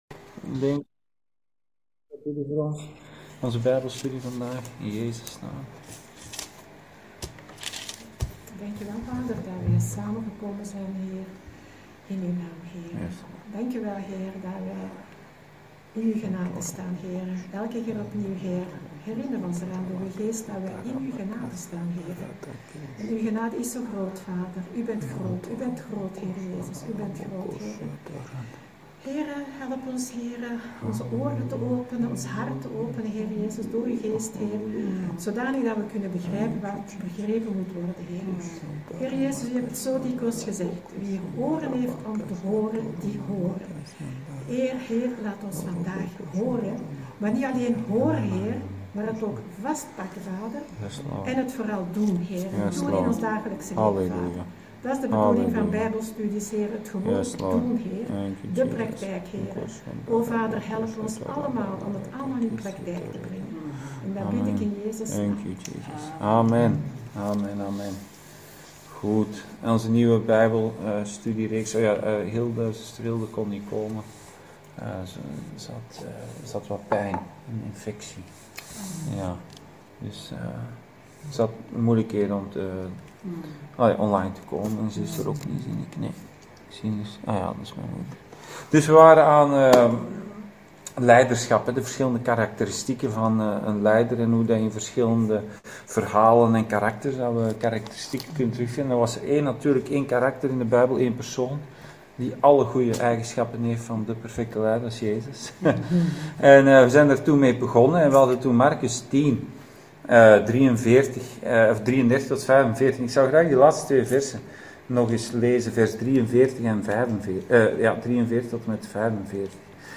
Bijbelstudie: Leiderschap: dienaar